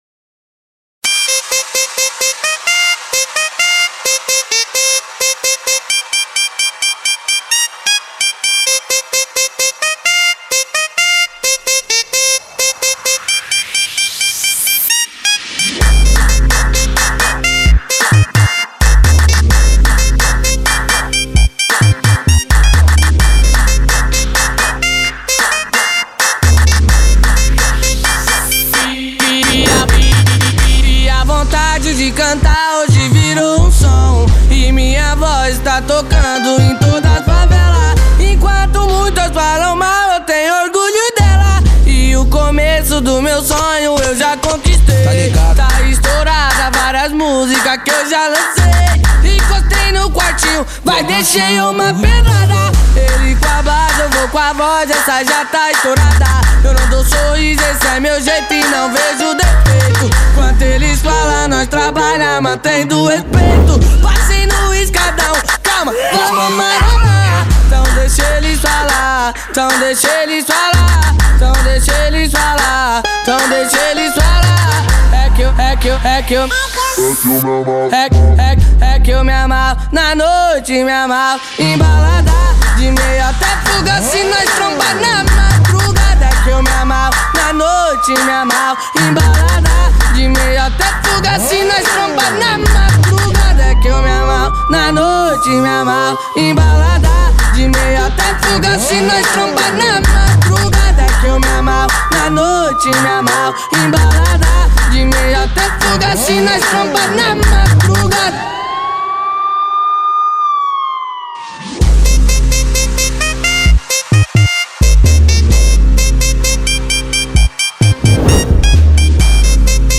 2024-02-16 23:33:47 Gênero: Funk Views